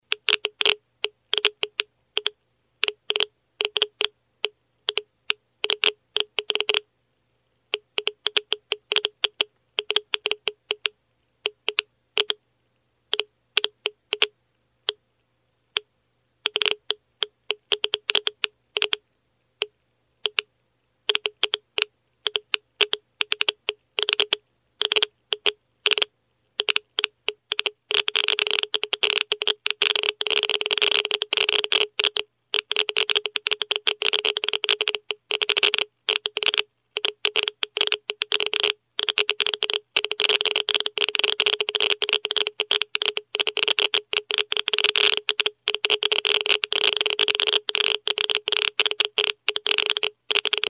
Звуки излучения
На этой странице собраны звуки, связанные с излучением: от фонового космического шума до специфических электромагнитных колебаний.